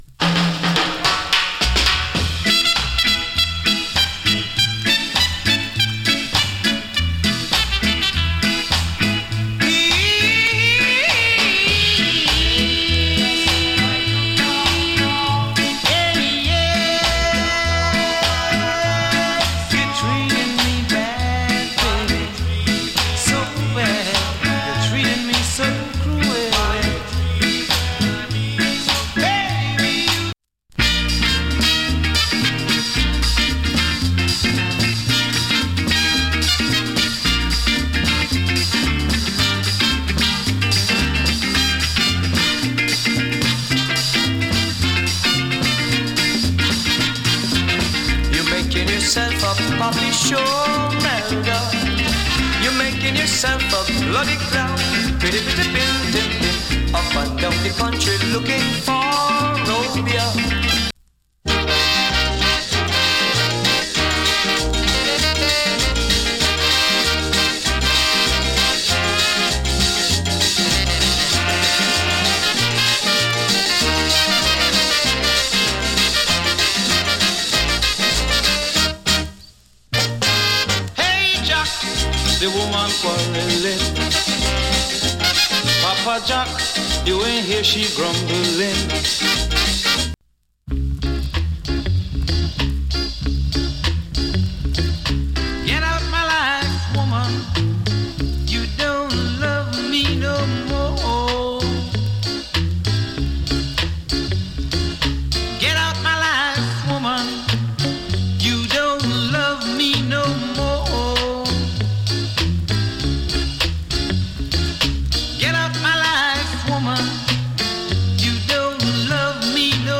チリ、パチノイズわずかに有り。
KILLER ROCK STEADY から CALYPSO までの好内容 !